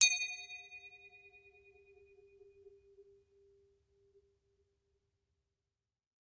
Triangle Medium Strike Spins 3